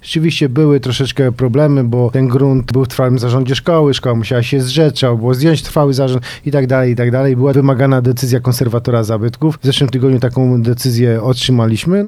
Nie ma jakichkolwiek przeciwwskazań i chcemy te grunty przekazać Stowarzyszeniu jak najszybciej, mówi wiceprezydent Jerzy Zawodnik: